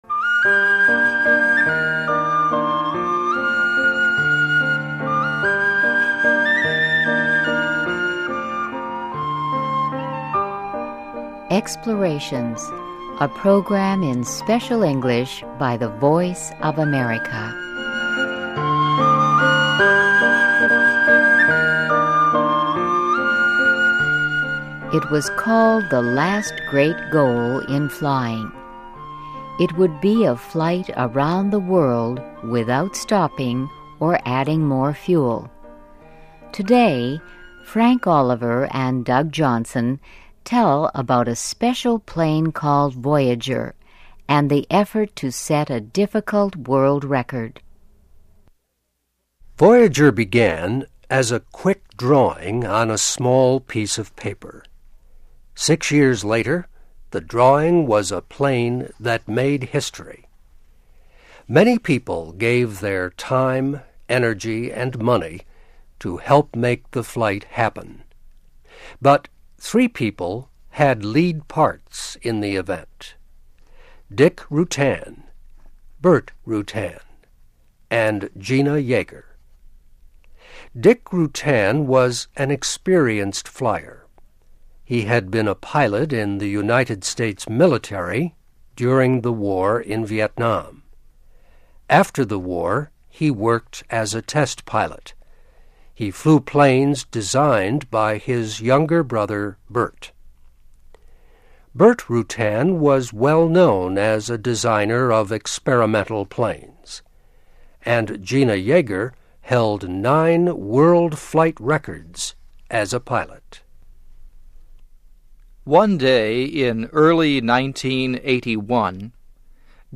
EXPLORATIONS -- a program in Special English by the voice of America.